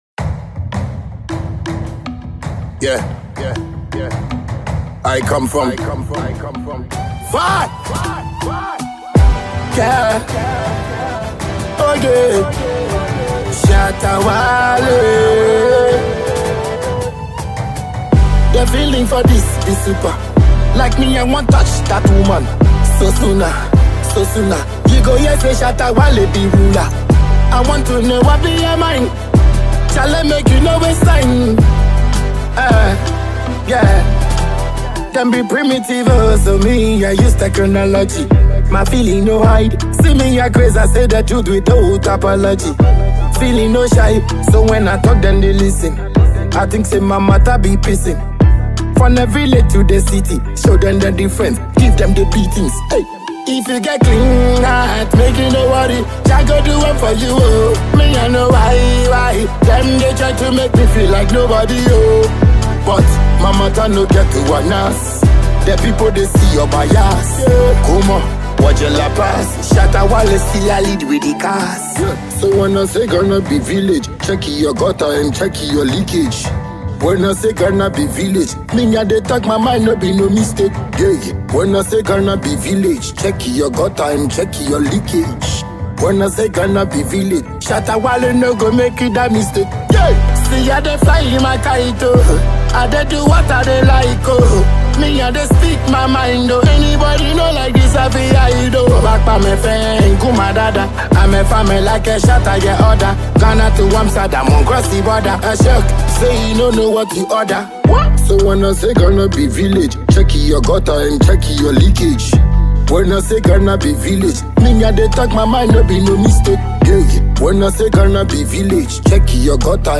dance hall